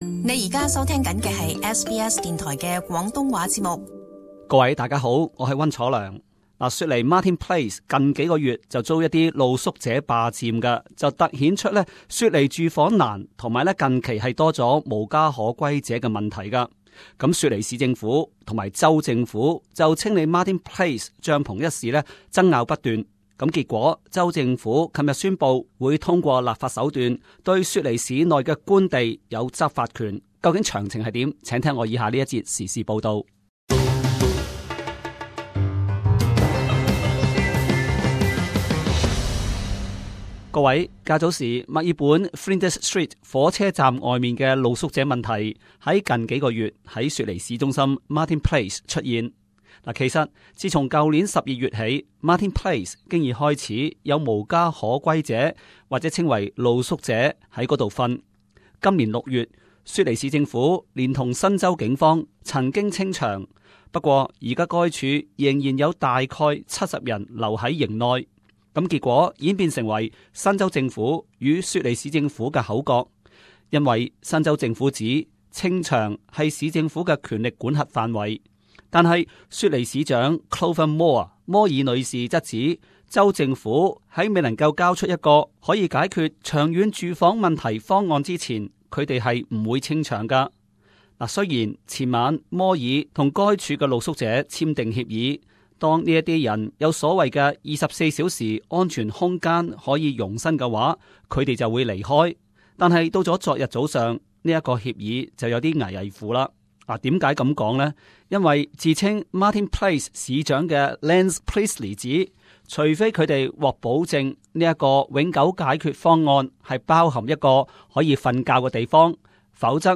【時事報導】 新州政府通過立法解決 Martin Place 露宿者問題